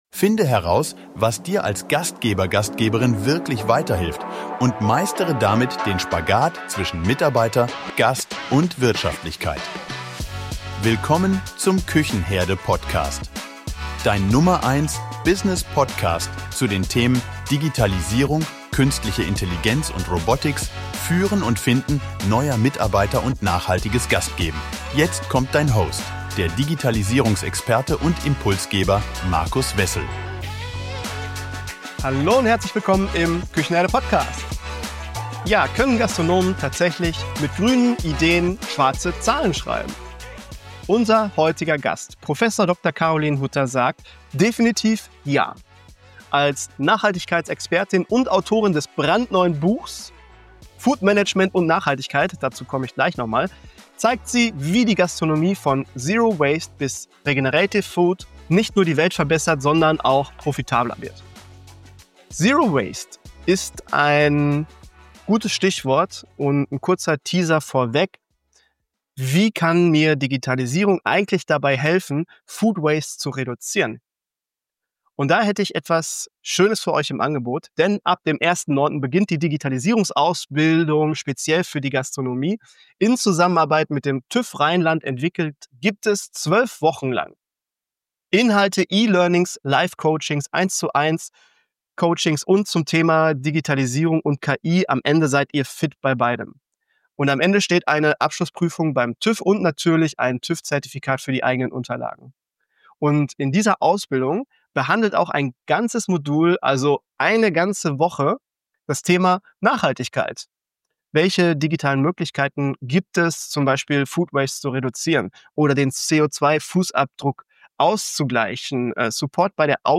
In diesem Interview sprechen wir über die 1,3 Milliarden Tonnen verschwendeter Lebensmittel jährlich, warum das Gastgewerbe für 40% der Food Waste verantwortlich ist und wie bereits ein Liter Speisereste 4 Euro Kosten verursacht.